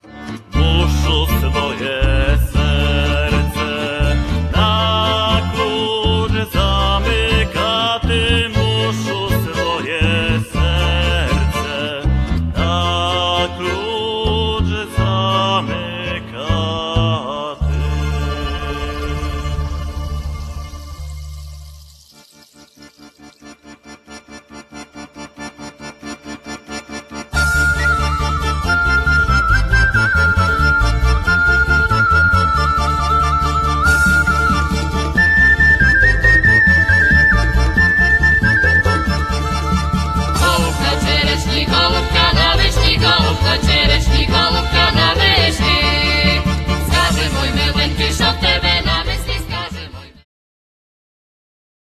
Są na nim pieśni ukraińskie, białoruskie i łemkowskie.
bębny, instrumenty perkusyjne
skrzypce